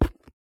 Concrete Steps.ogg